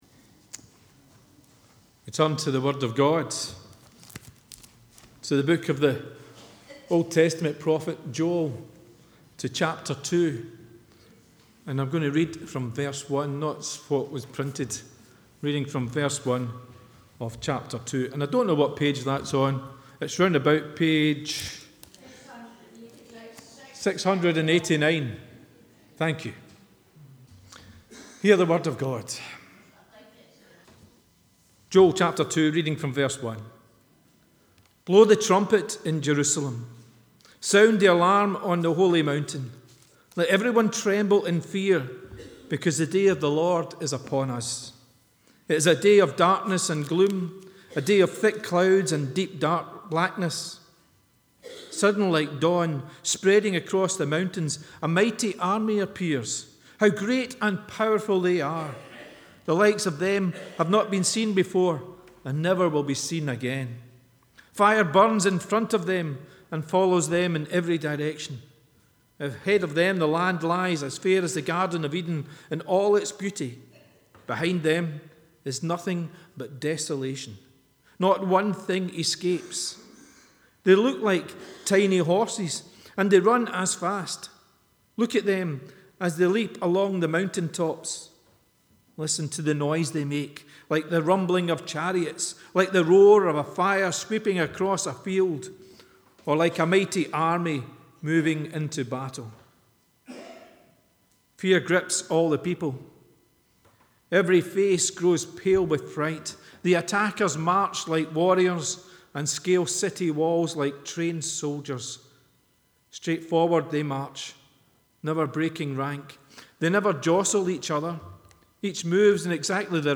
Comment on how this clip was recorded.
The readings prior to the sermon are Joel 2: 28-32a & Acts 2: 1-4